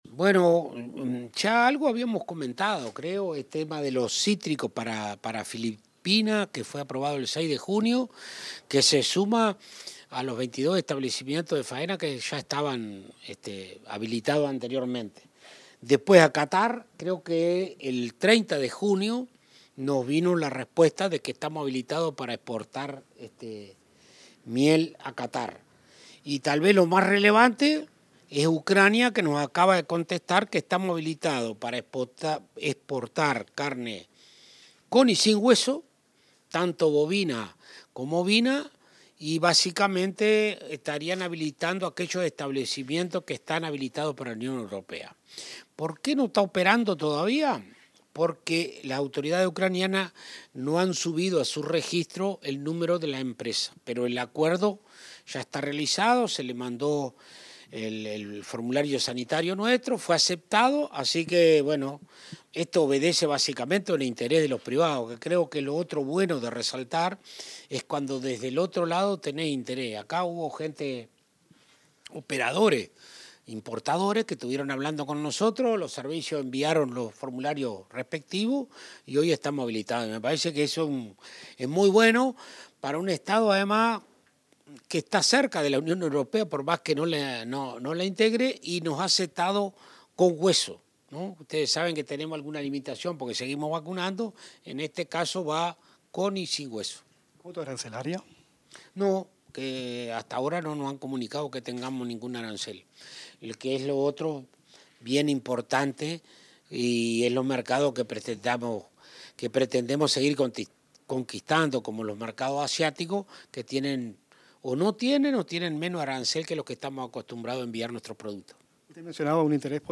Declaraciones del ministro de Ganadería, Agricultura y Pesca, Alfredo Fratti